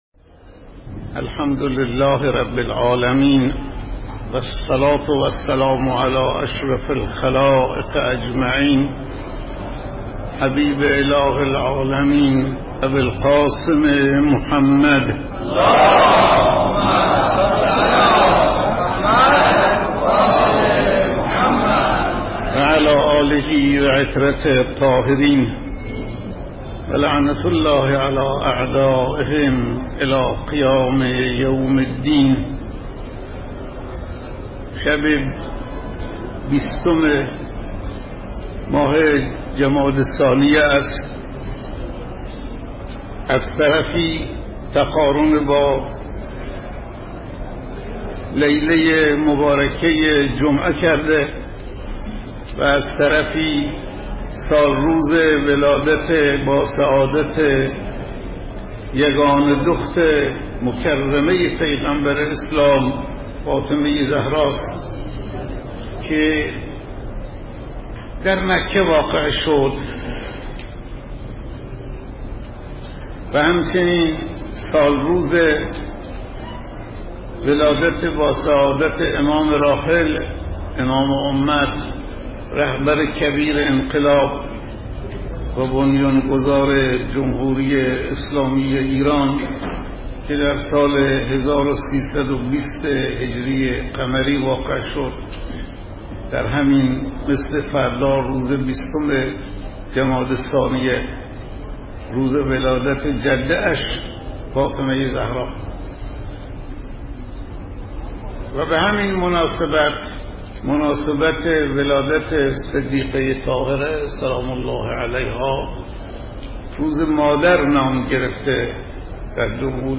سخنرانی شب ولادت حضرت فاطمه زهرا سلام الله علیها (صوتی) :: پایگاه خبری مسجد حضرت آیت الله شفیعی اهواز
به مناسبت فرا رسیدن ولادت باسعادت حضرت فاطمه زهرا سلام‌الله‌علیها و همچنین سالروز ولادت حضرت امام خمینی (ره) مراسم جشنی با سخنرانی حضرت آیت الله شفیعی در روز پنج شنبه 20 فروردین ماه برگزار شد...